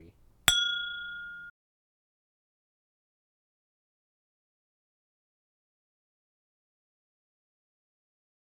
OWI_Crystal class ping
chime crystal ding ping ting sound effect free sound royalty free Sound Effects